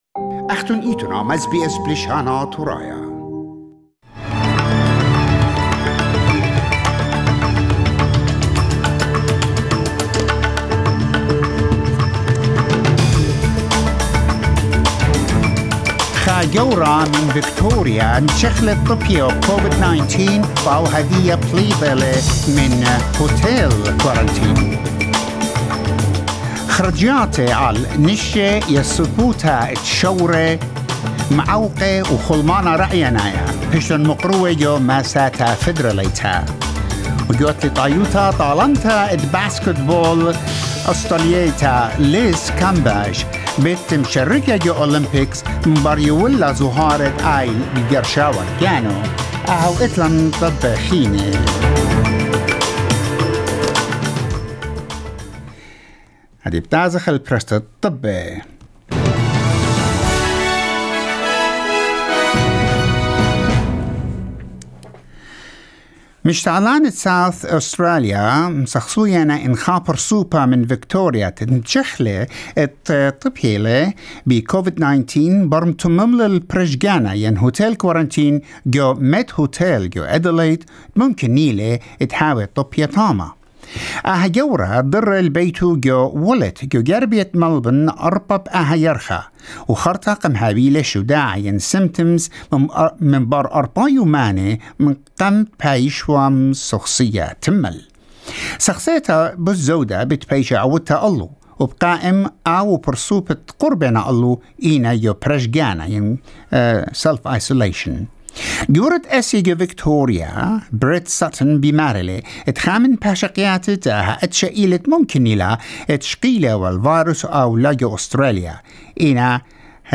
SBS News in Assyrian